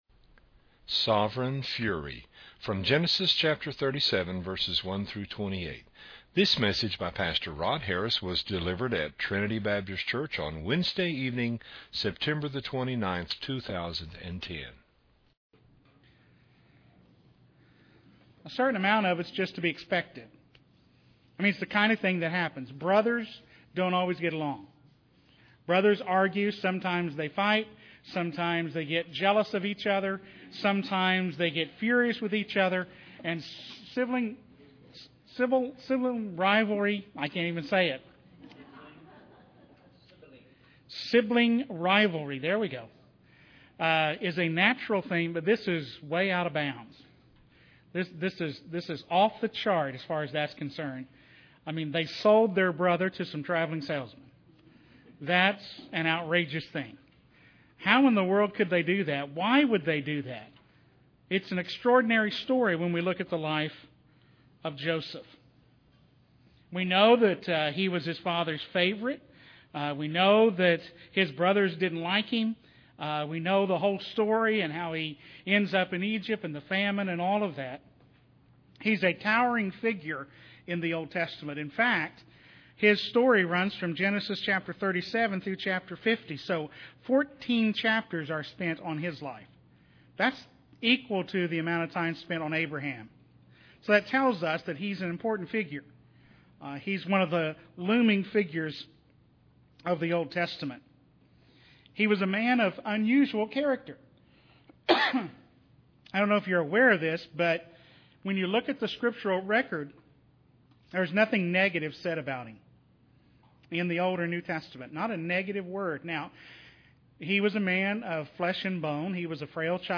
An exposition of Genesis 37:1-28
delivered at Trinity Baptist Church on Wednesday evening, September 29, 2010.